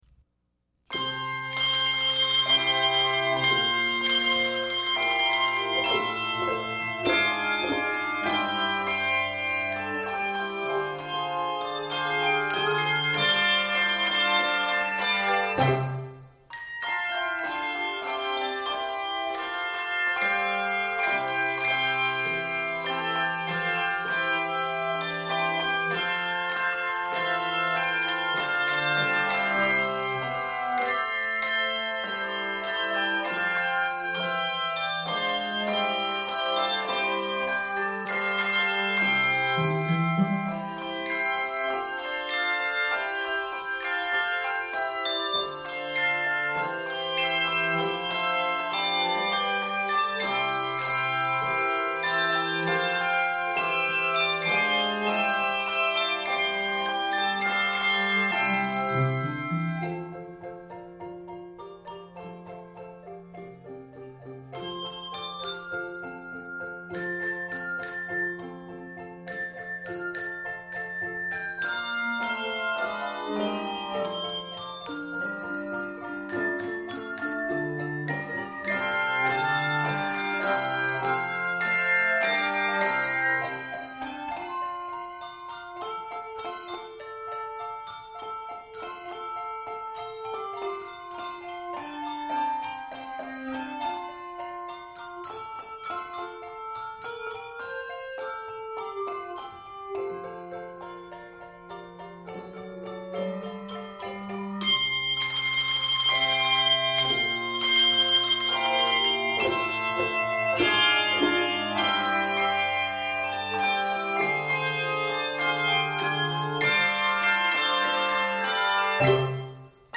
With driving rhythms and liberal syncopation
Keys of C Major and F Major.
Octaves: 3-6